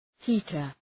{‘hi:tər}